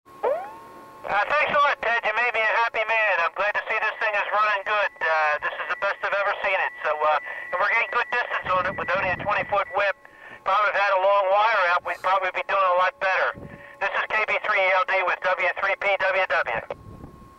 Radio squads were deployed to the summit of Big Pocono Mountain for this year's field exercise.
The rest are off the air via the  GRC-9 receiver.